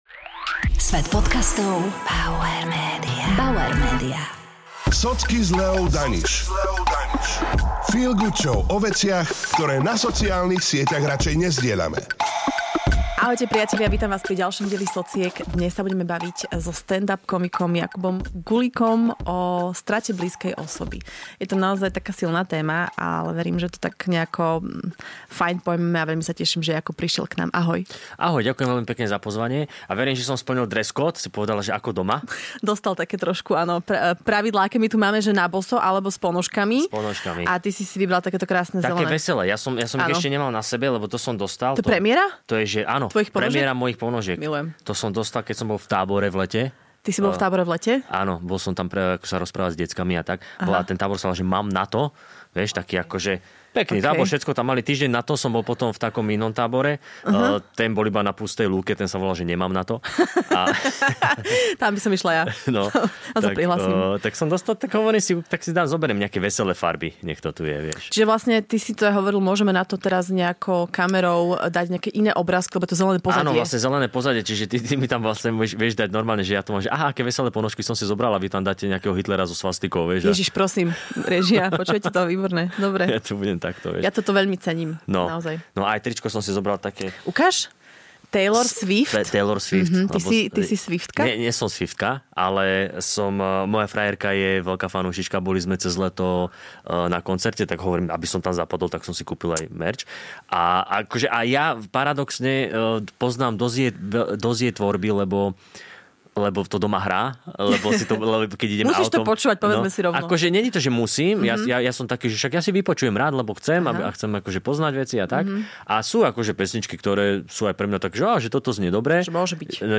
Cez veľmi úprimné rozhovory jeden na jedného ti priblíži ich skutočný svet, ktorý na sociálnych sieťach všetci často schovávame.